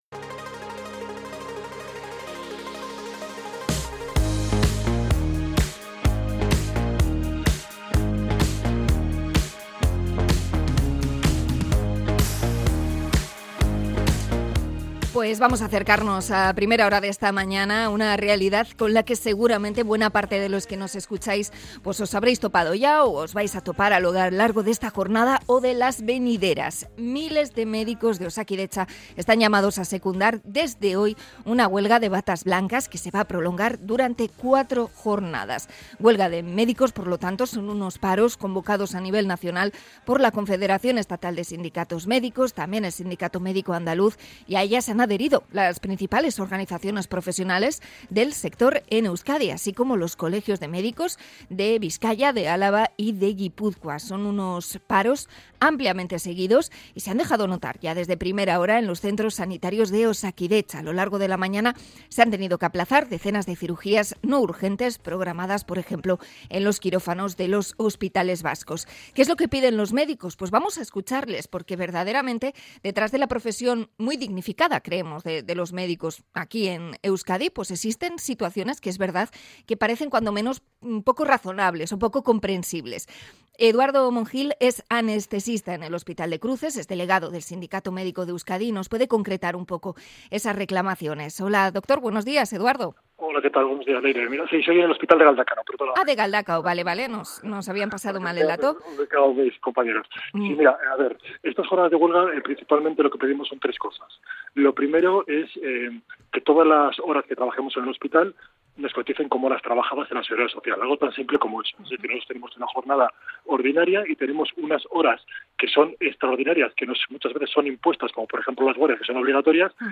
Entrevista con el Sindicato Médico de Euskadi por la huelga